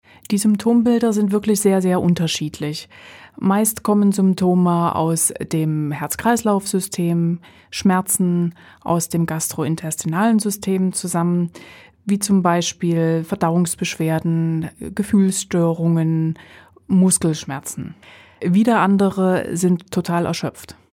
O-Töne26.02.2026